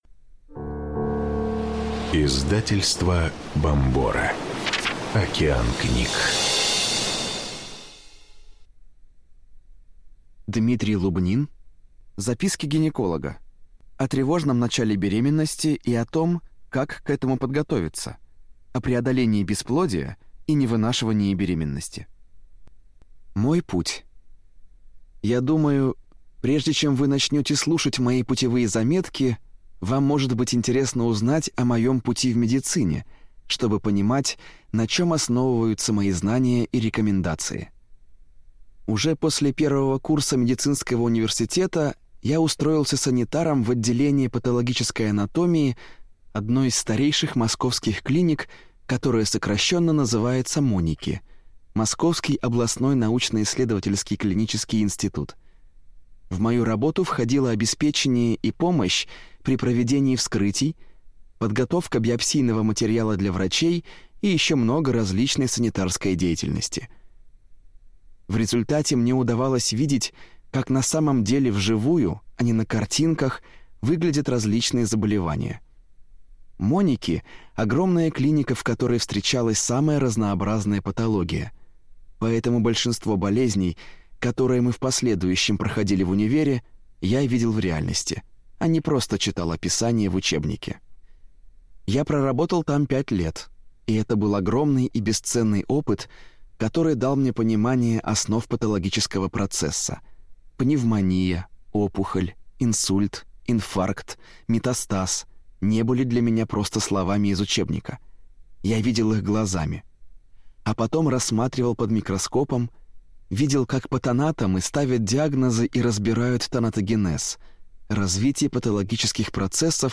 Аудиокнига Записки гинеколога: о тревожном начале беременности и том, как к этому подготовиться, о преодолении бесплодия и невынашивании беременности | Библиотека аудиокниг